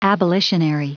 Prononciation du mot abolitionary en anglais (fichier audio)
Prononciation du mot : abolitionary